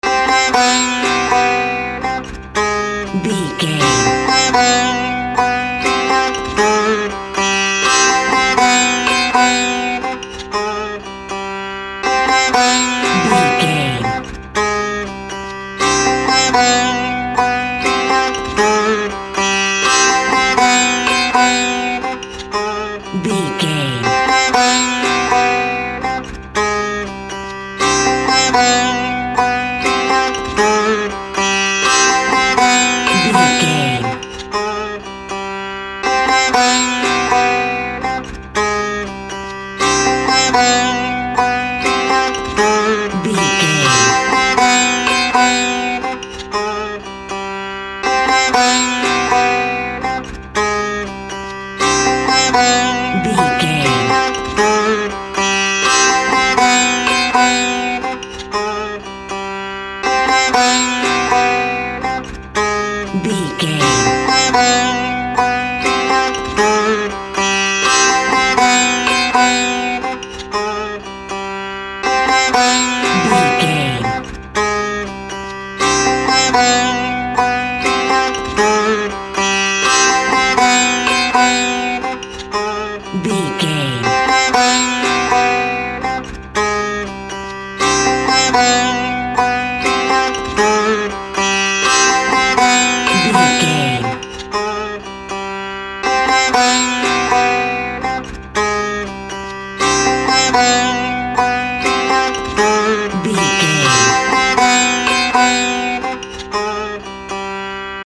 Aeolian/Minor
ethnic music